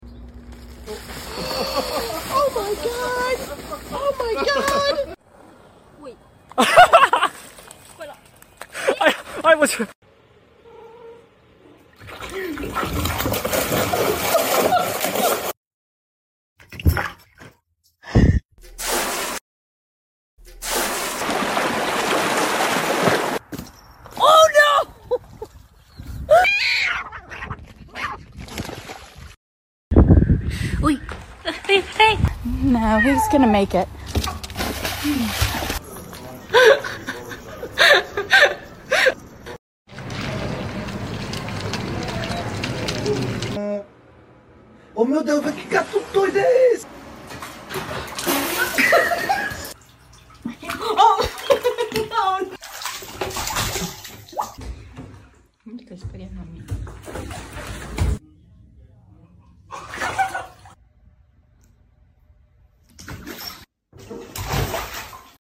Part 21 | These cats sound effects free download